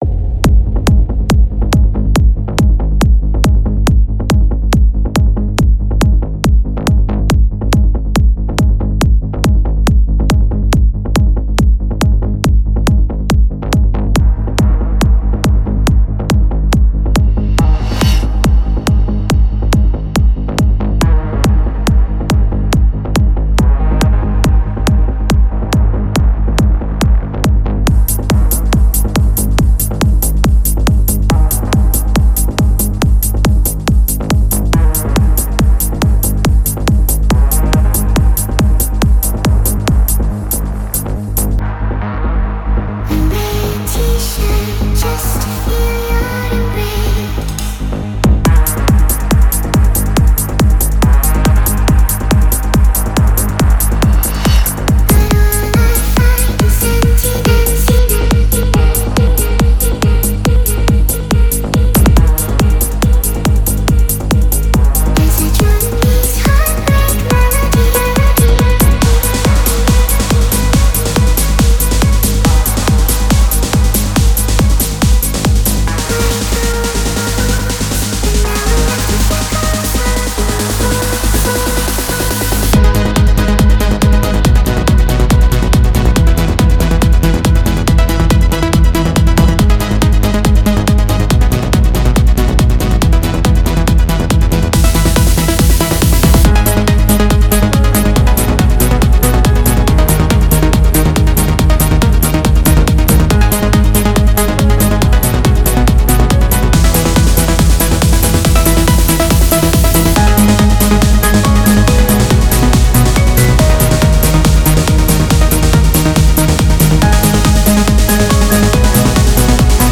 Real Synthetic Audio EBM and Industrial net radio show blog and RSS feed.